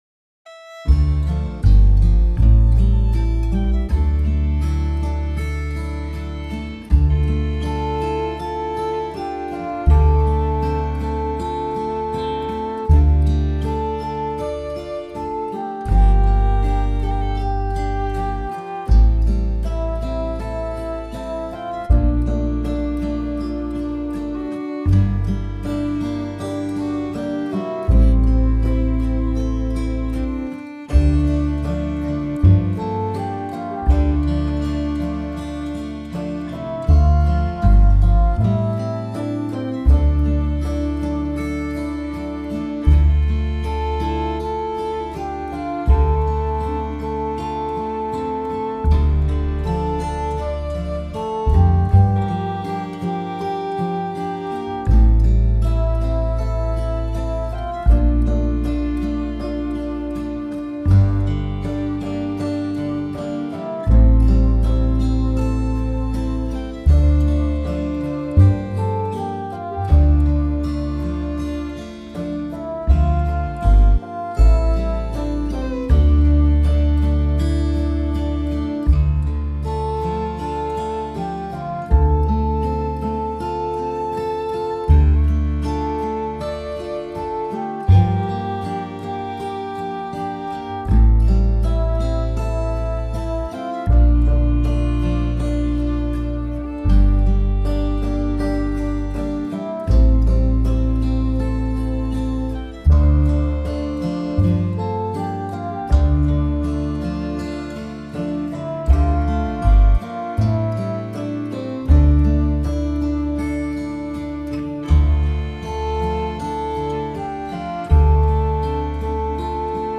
BIAB can’t follow the natural rhythm of anything: